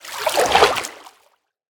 assets / minecraft / sounds / liquid / swim9.ogg
swim9.ogg